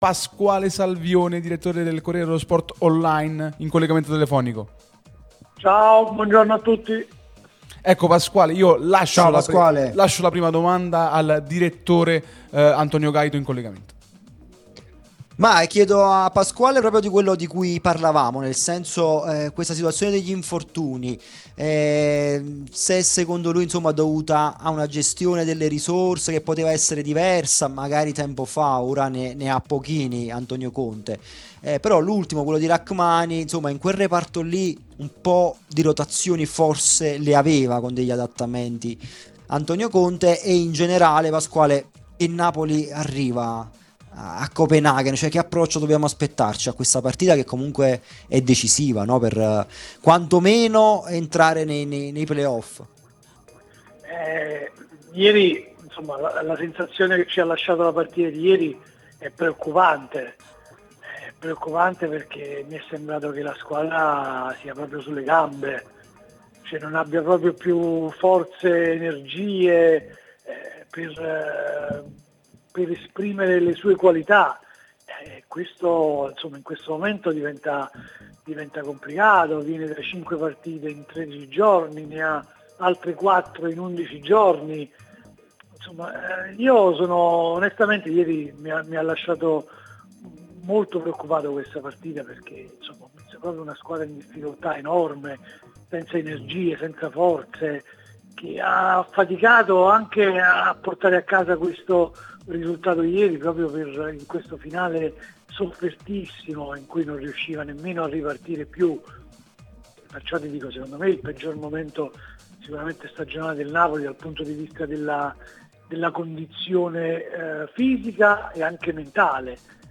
prima radio tematica sul Napoli